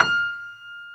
55p-pno33-F5.wav